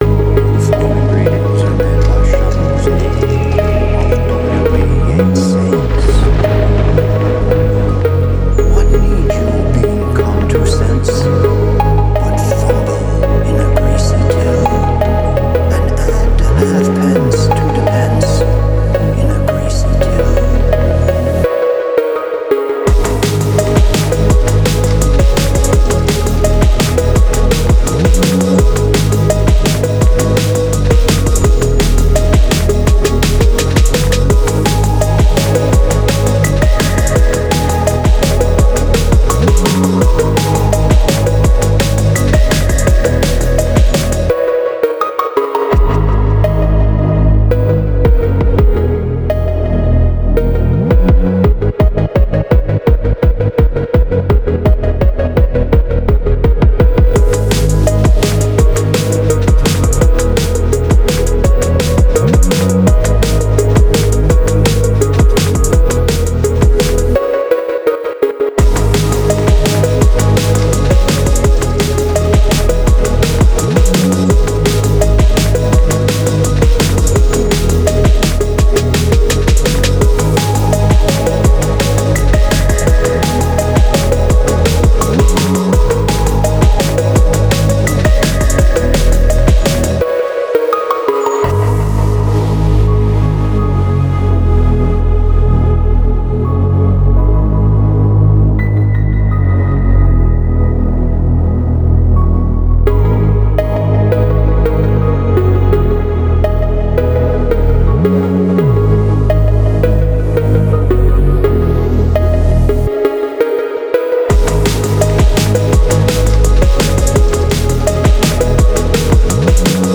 Категория: Фонк музыка